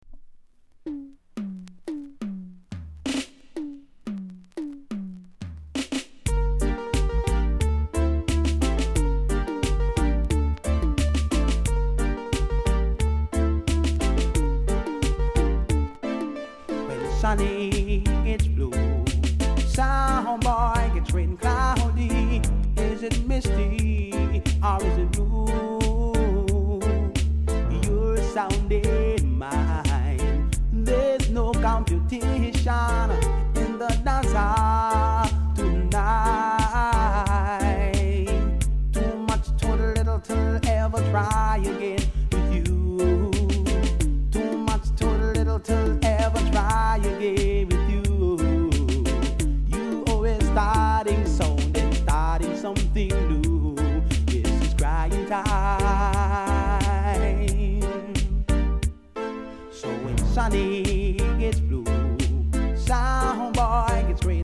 ※多少小さなノイズはありますが概ね良好です。
コメント KILLER DIGI!!RARE!!